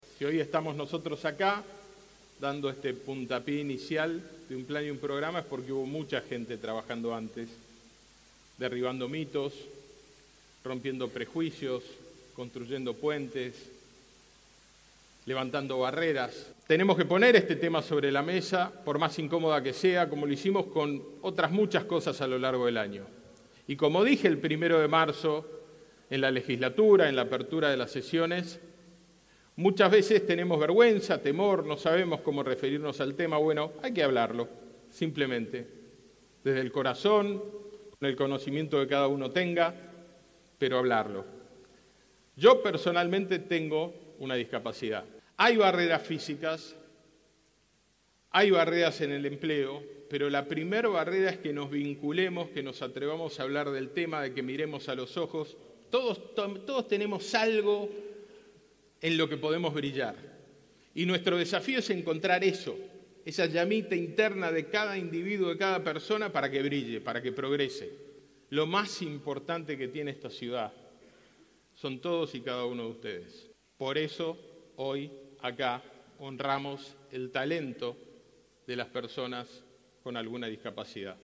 En una ceremonia muy emotiva en el Teatro Colón anunciaron un conjunto de medidas que buscan generar mayor inclusión y respuestas, además de un cambio cultural en la sociedad.
Audio del Jefe de Gobierno sobre el primer Plan de Discapacidad
Audio de Jorge Macri.mp3